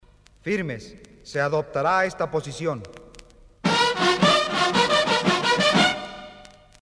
TOQUES MILITARES REGLAMENTARIOS EN MP3.